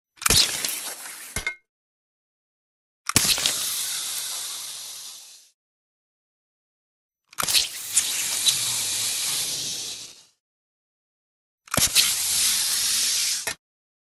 Звуки Человека-Паука, паутины
Выстрелы паутины при полёте ЧП